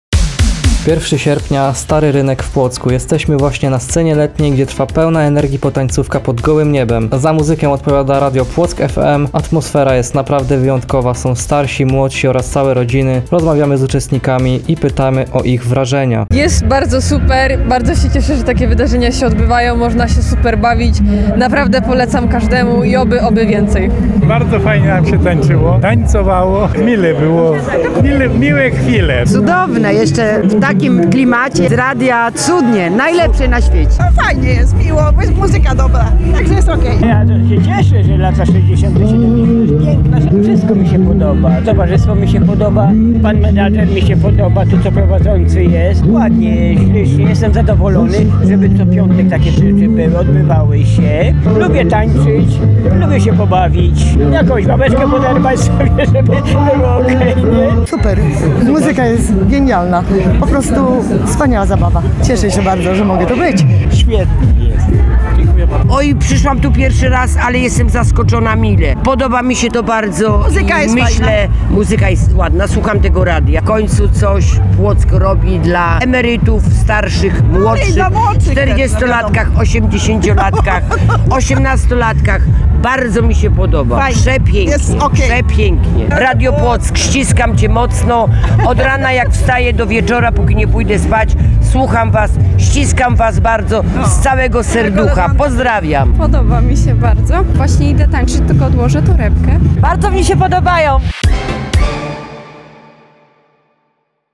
Piątkowa Potańcówka na Starówce – Dyskoteka 40-latka z Radiem Płock FM.